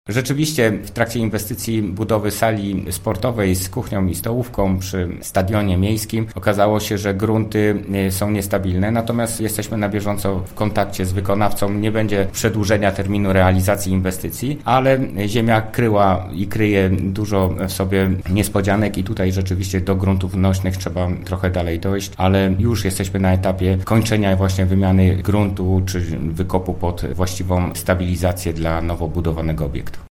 – Oczywiście wykonawca da sobie z tym radę i termin oddania sali do użytku zostanie dotrzymany – zapewnia Remigiusz Lorenz, burmistrz Międzyrzecza: